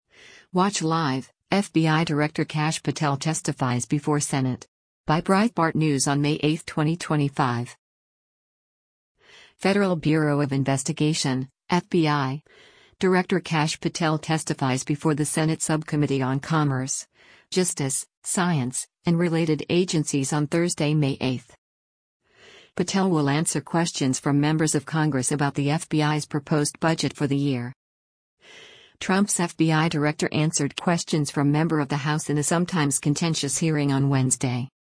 Federal Bureau of Investigation (FBI) Director Kash Patel testifies before the Senate Subcommittee on Commerce, Justice, Science, and Related Agencies on Thursday, May 8.